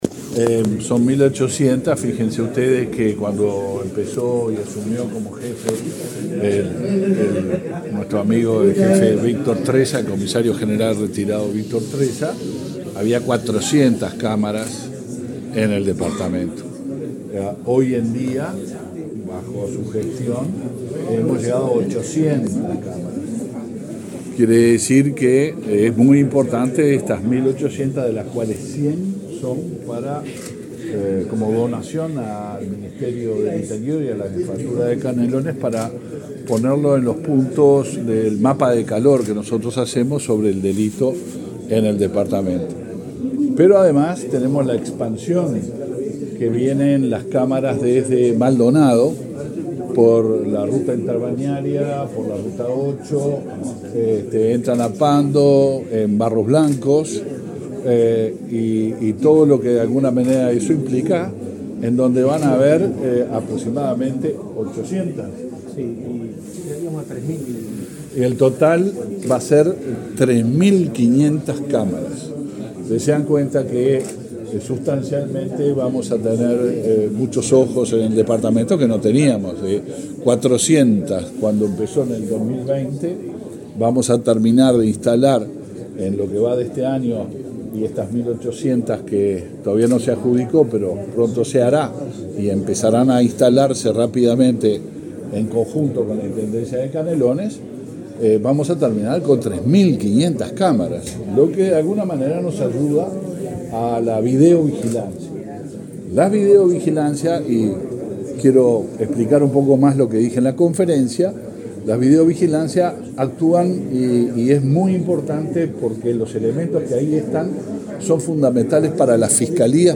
Declaraciones del ministro del Interior, Luis Alberto Heber
Luego dialogó con la prensa.